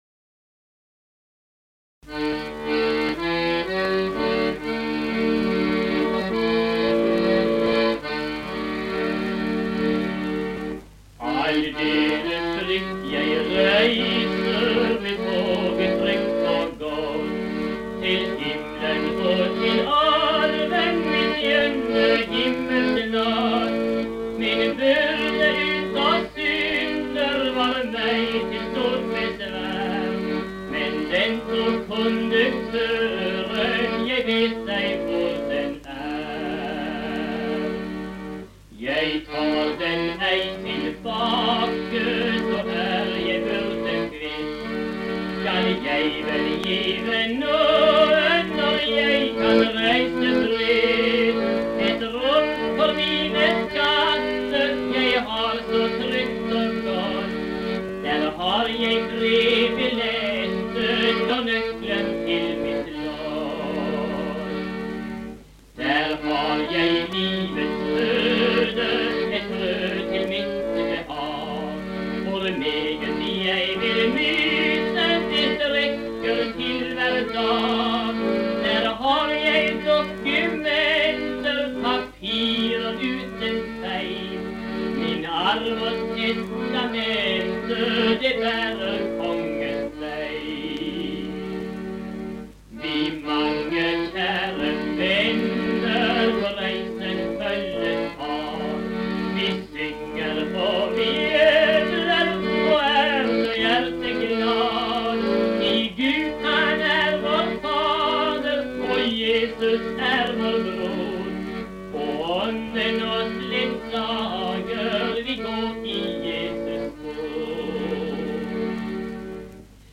Aldeles fritt jeg reiser er en gammel leservise med ukjent forfatter og komponist.
sunget inn på plate